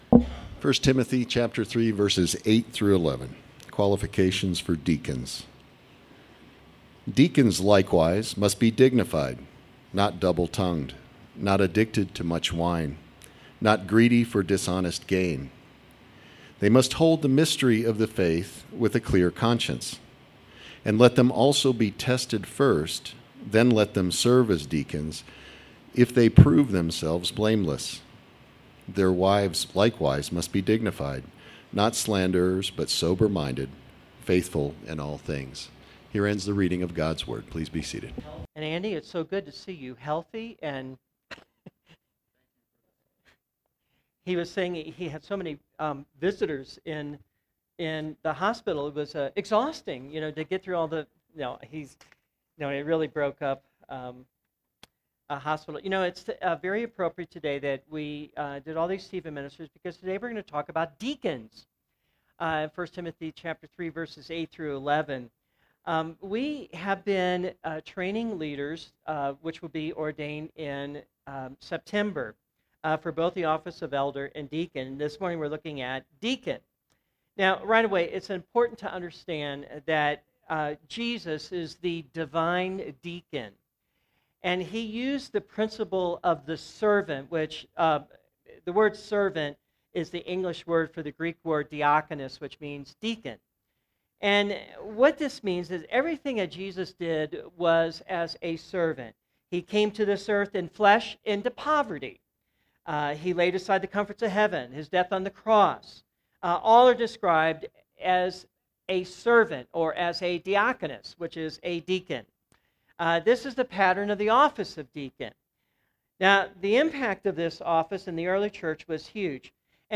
Sermons - Redeemer Presbyterian Church